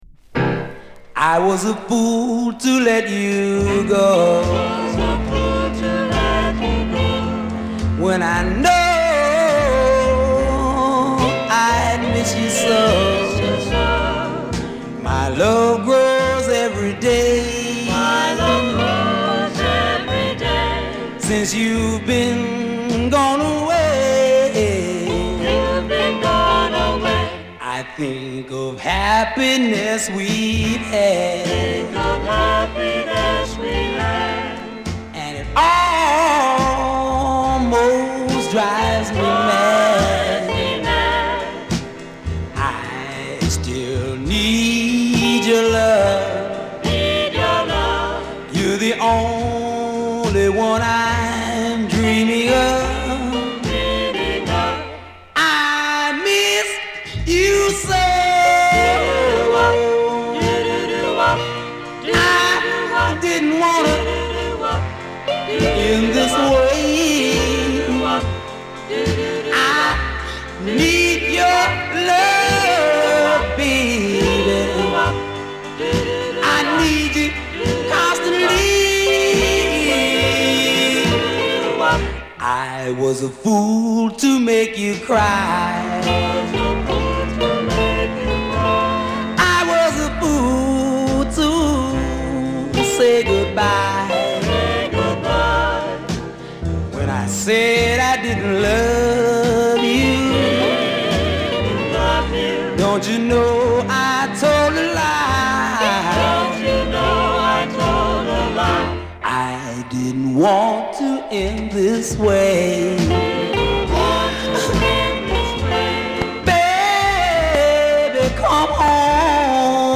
エンディング付近にプレスミスgaあり（両面とも同じ箇所にキズのようなあと）、音に影響します（写真3）。